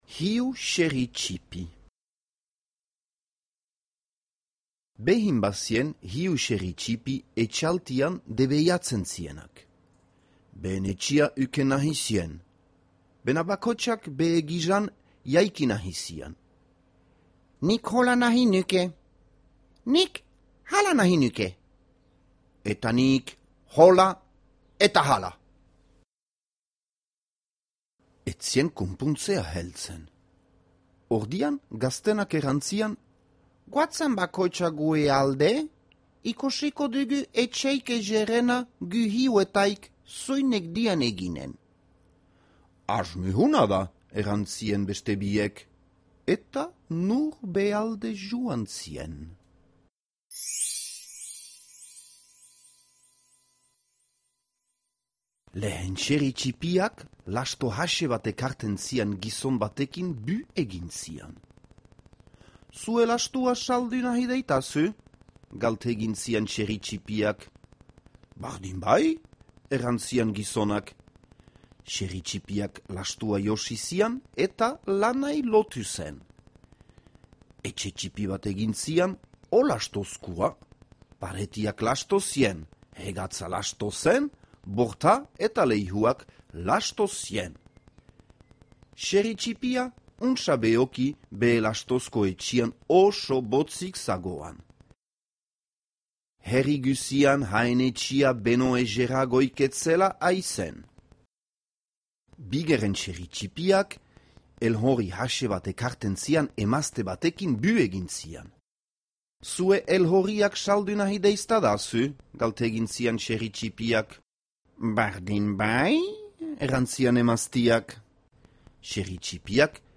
Hiru xerri ttipi - Zubereraz - ipuina entzungai